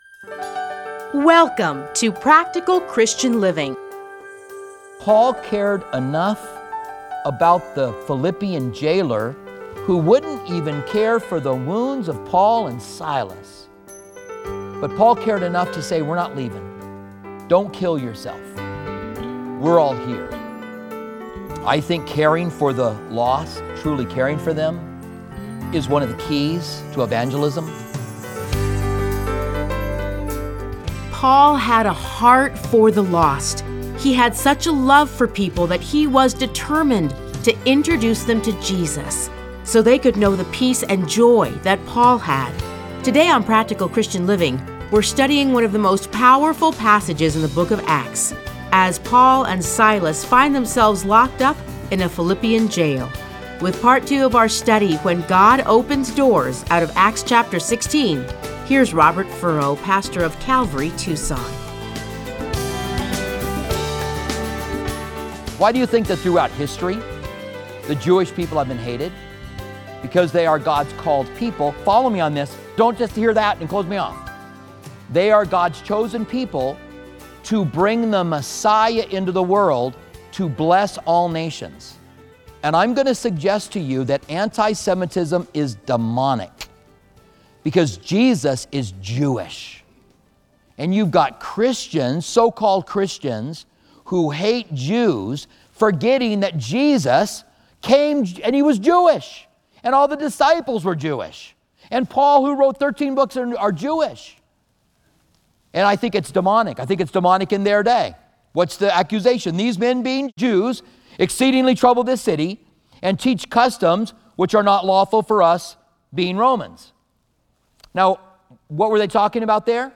Listen to a teaching from Acts 16:16-40.